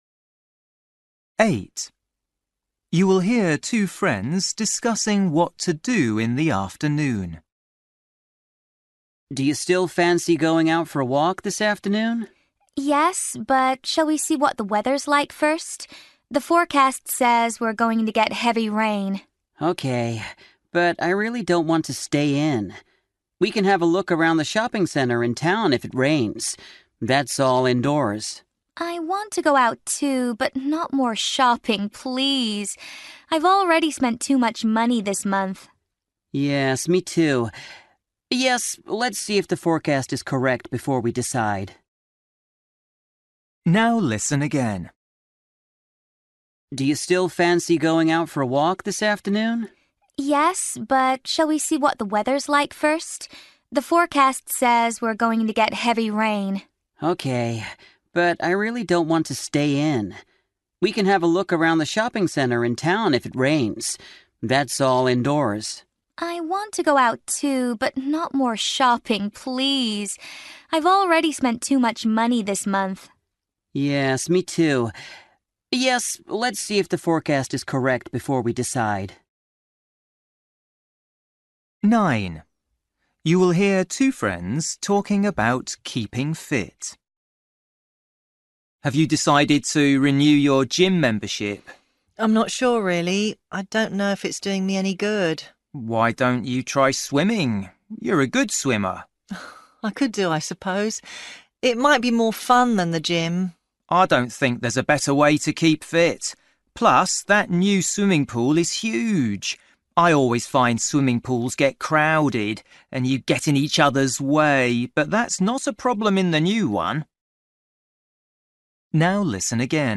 Listening: everyday short conversations
You will hear two friends discussing what to do in the afternoon.
You will hear two friends talking about keeping fit.
10   You will hear a woman telling a friend about a handbag.
12   You will hear two friends talking about a storm.